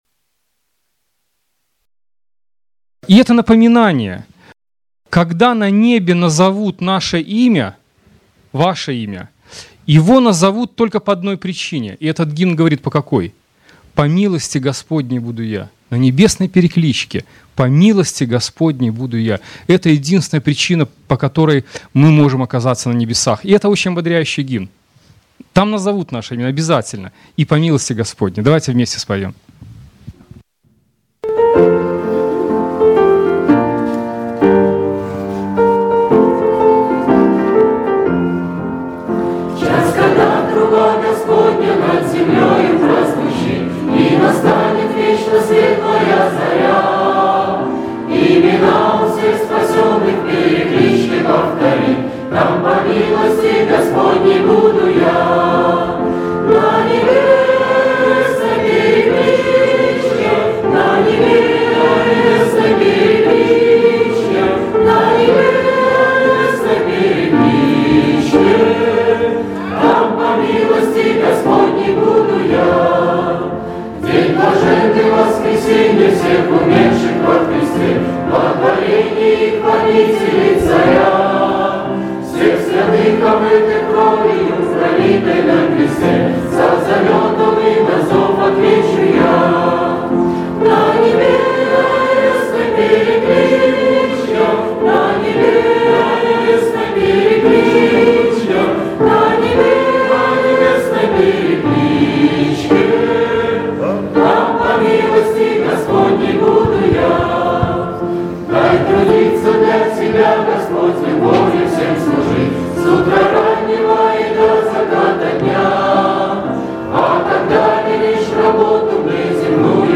В час, когда труба Господня (Общее пение)